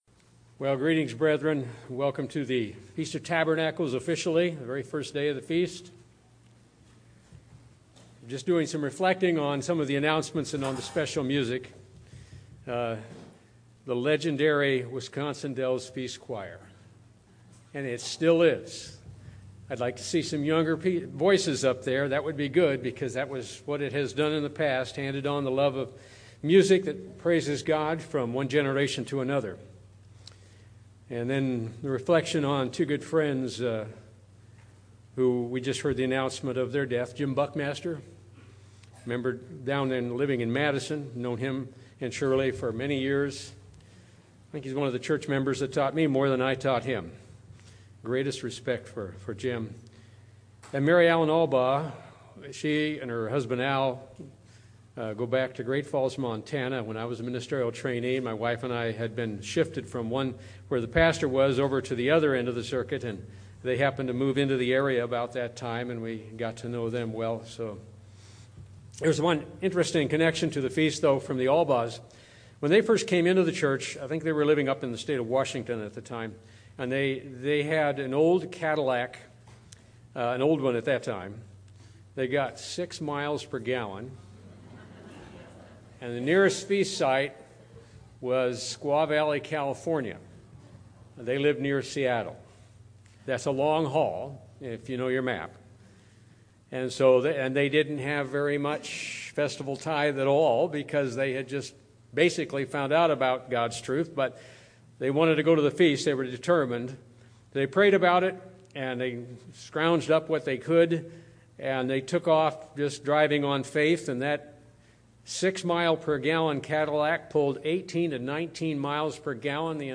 This sermon was given at the Wisconsin Dells, Wisconsin 2019 Feast site.